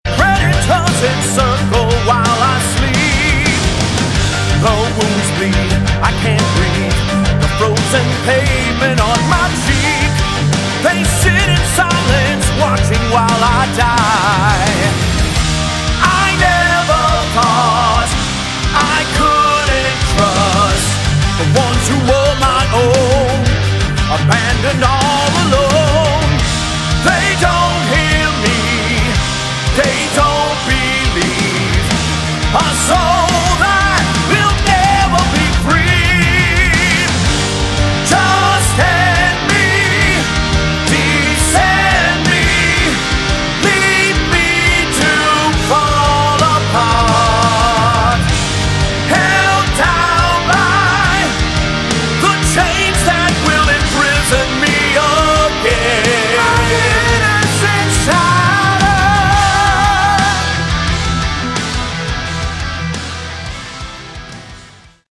Category: Melodic Metal
vocals
guitars
bass
drums
kayboard, piano
synth and orchestral arrangements